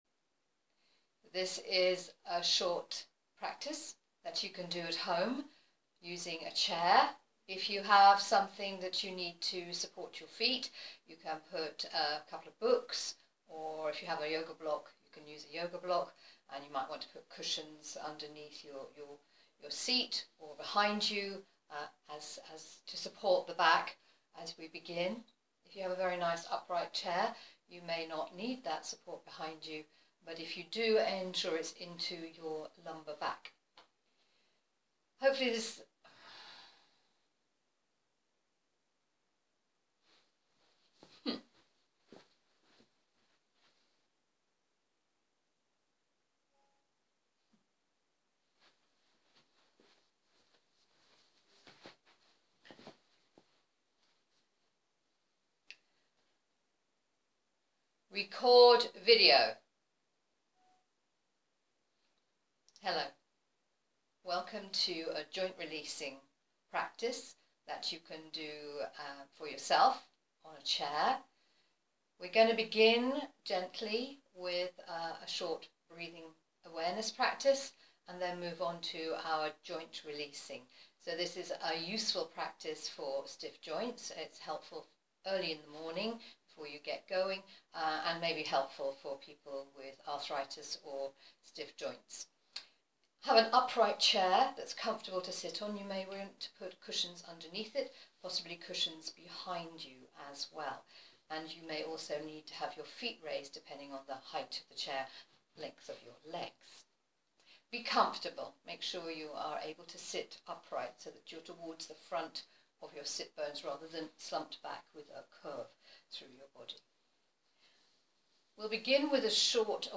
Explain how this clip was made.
These recorded sessions are gentle level and suitable for most fitness levels.